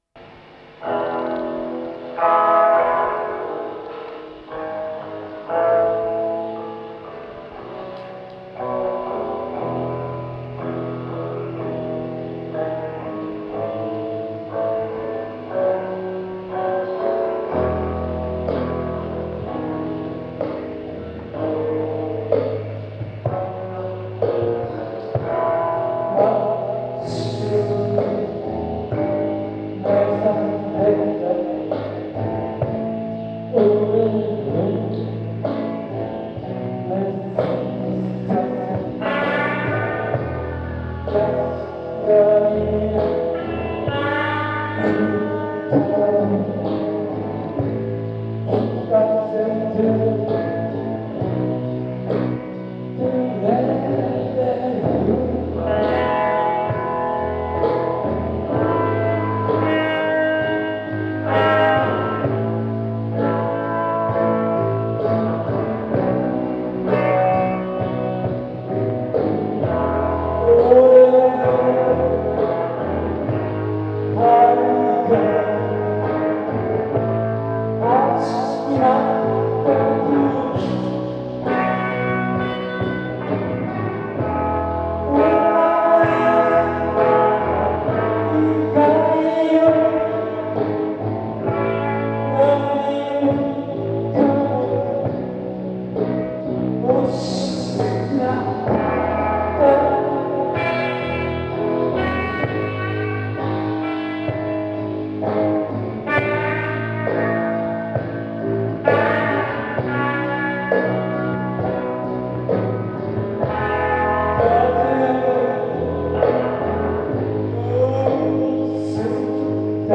ライヴより（'83.12.24　法政大学学館大ホール